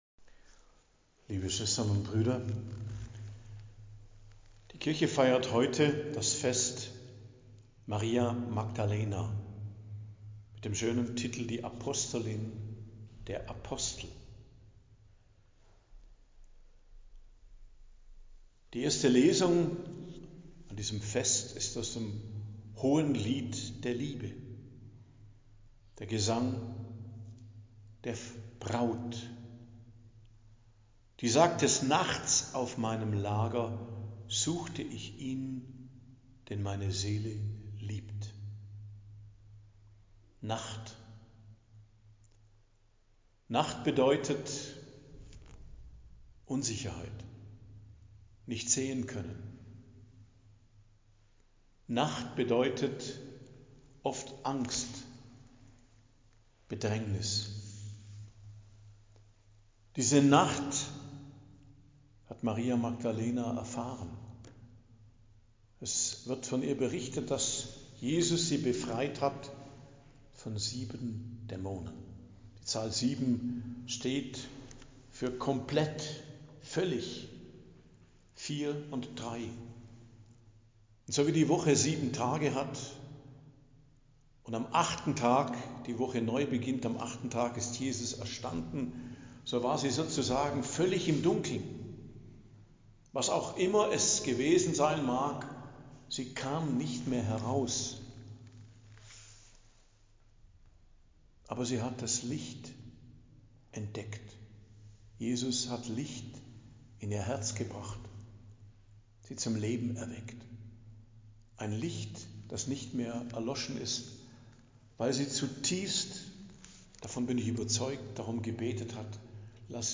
Predigt am Fest der Hl Maria Magdalena, Apostolin der Apostel, 22.07.2025